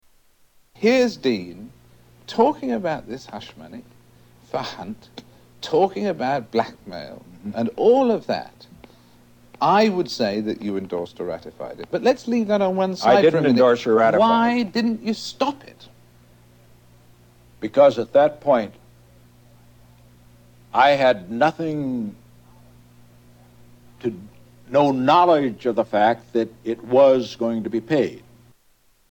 Tags: Historical Frost Nixon Interview Audio David Frost Interviews Richard Nixon Political